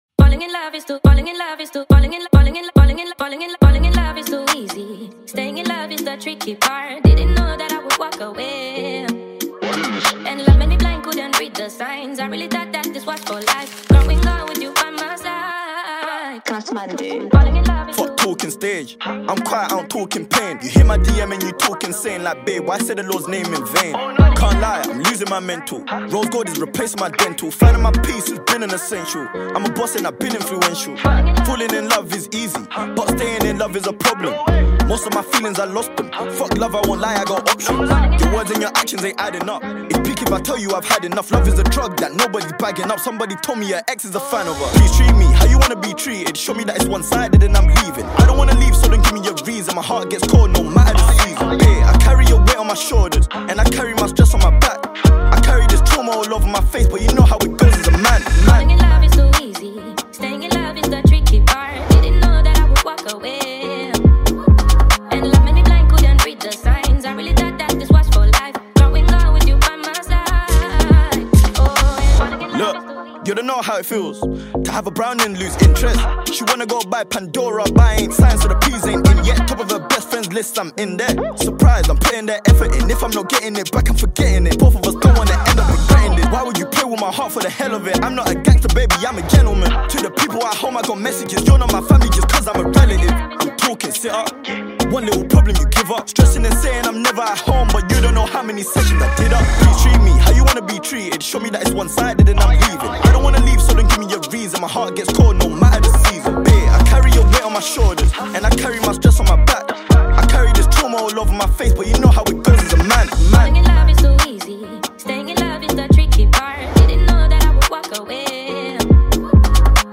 DanceHall And Afro Beat singer